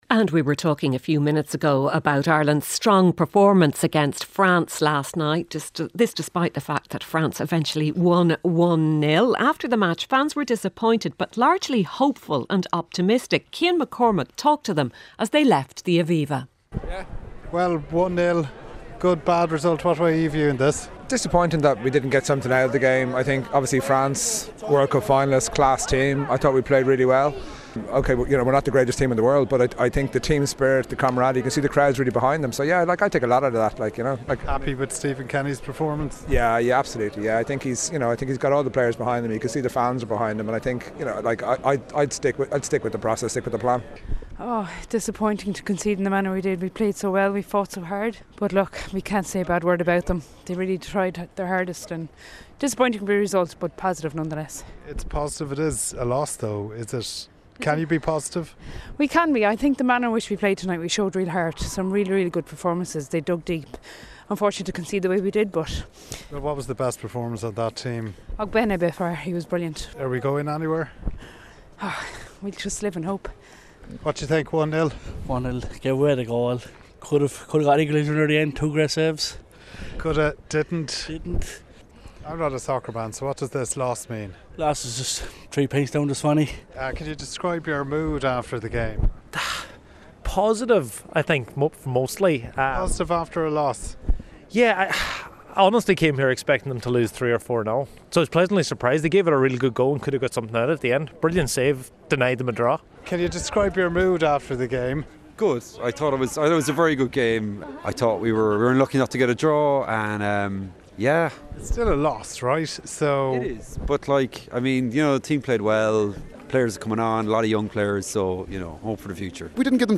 7:35am Sports News - 28.03.2023